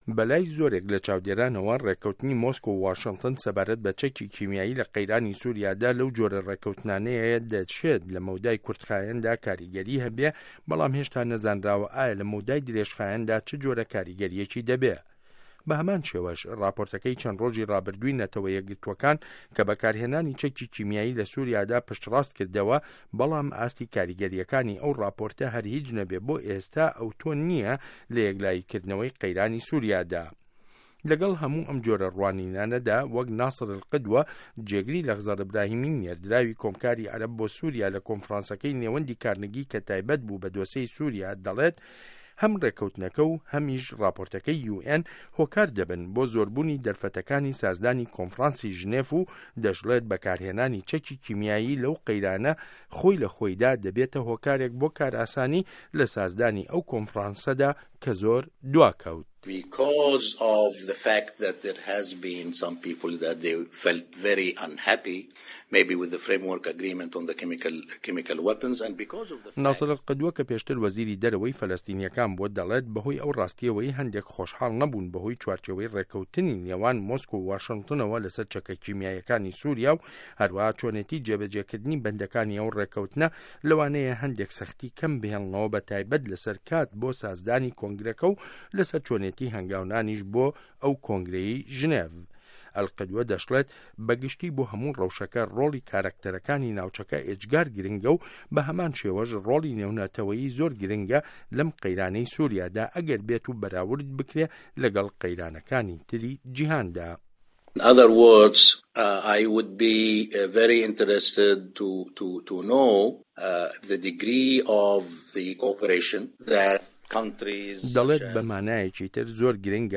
ڕاپۆرتی ڕه‌هه‌ندی ڕێکه‌وتنی واشنتۆن و مۆسکۆ له‌سه‌ر سوریا